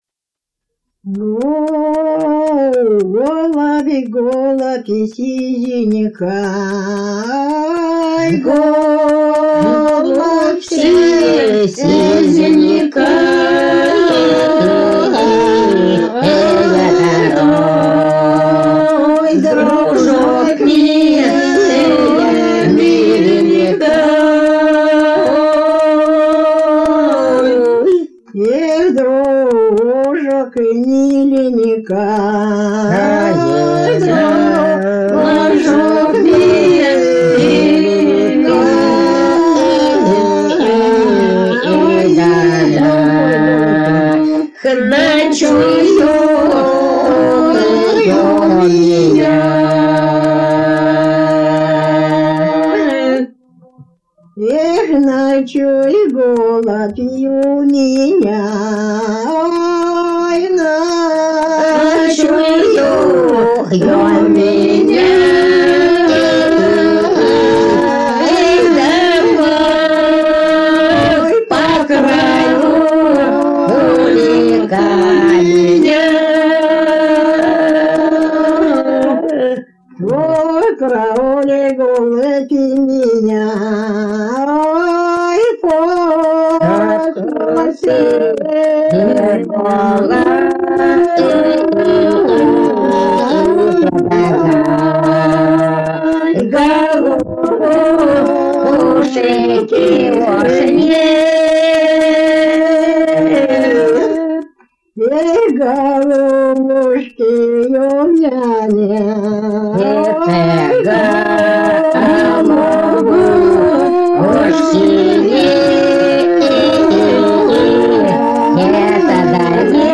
Народные песни Касимовского района Рязанской области «Голабь, голабь сизеникай», лирическая.